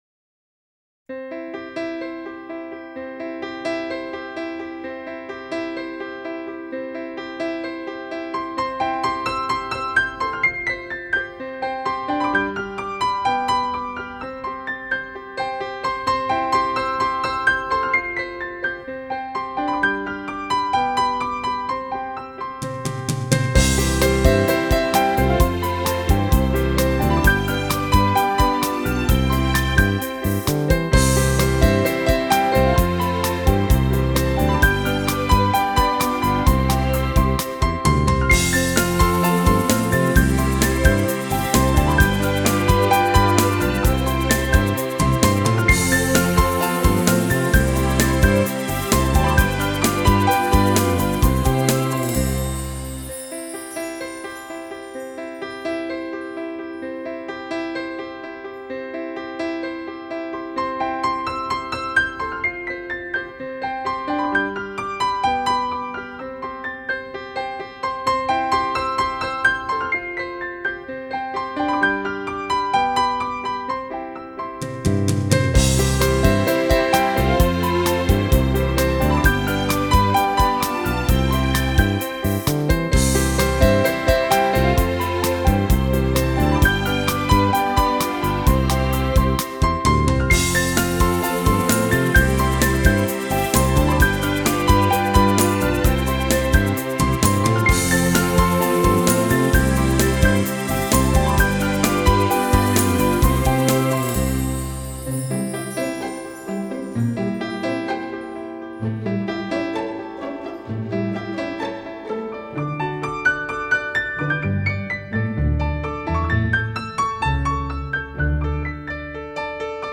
オリジナルもピアノの インストゥルメンタル 曲。
まさにオルゴールの上で踊る人形のような愛らしい曲。